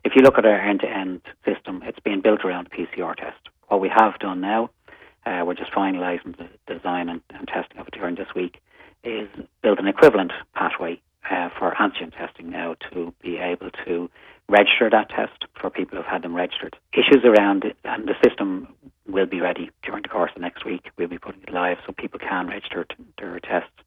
CEO of the HSE, Paul Reid says a new online system to allow people register a positive antigen test result should be available from next week: